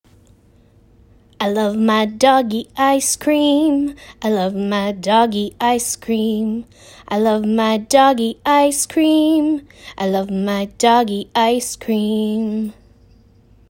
3. Pair a fun and engaging song with the story.